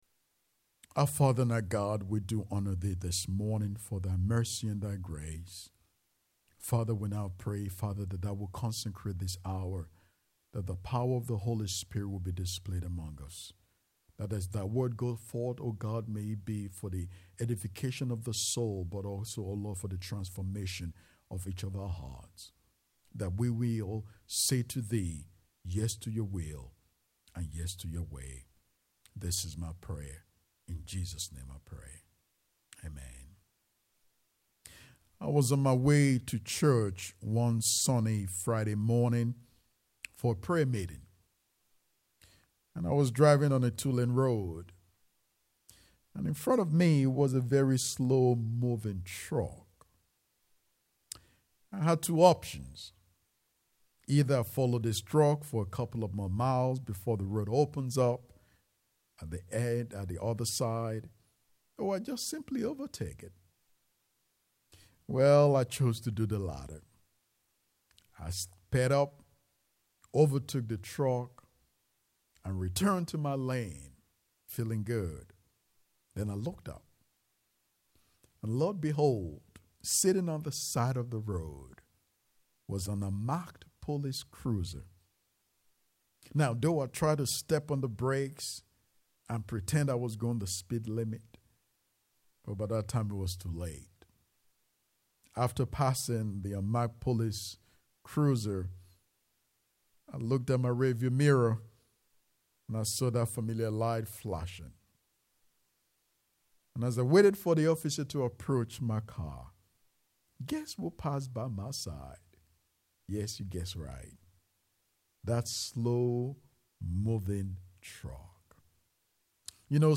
10:30 AM Service God is Never in a Hurry Click to listen to the sermon. https